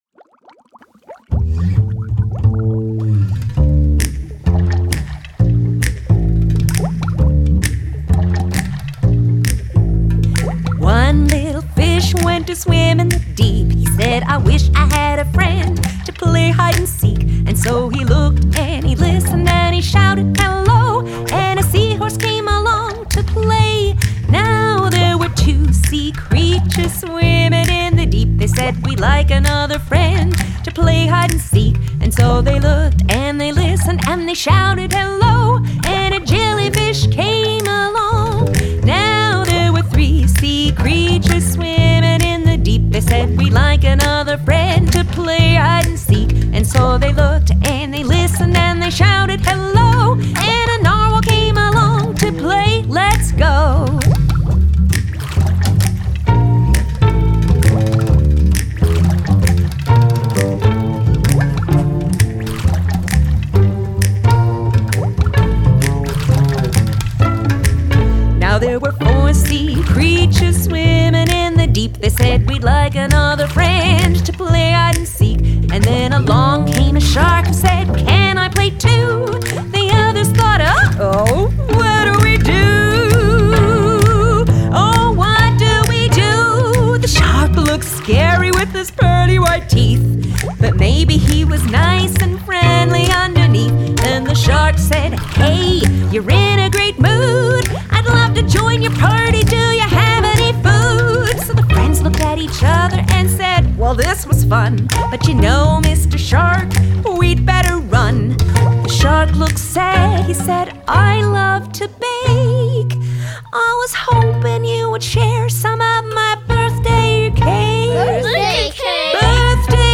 Children's